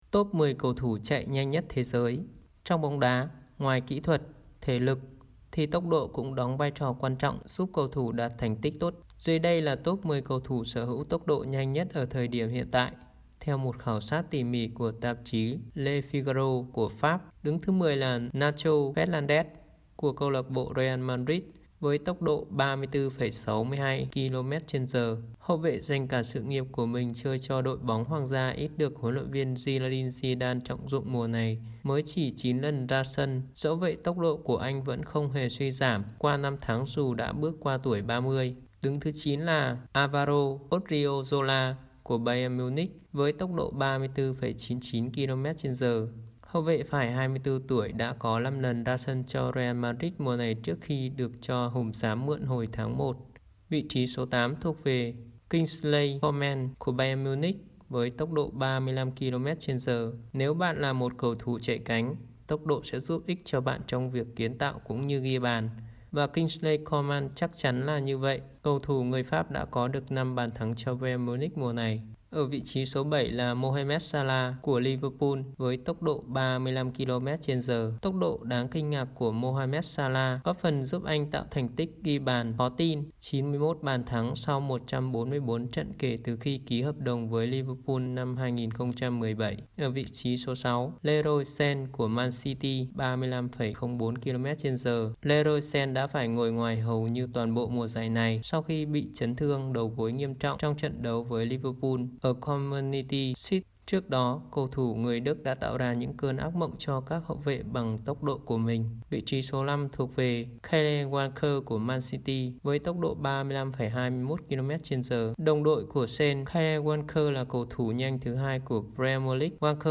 Bản tin audio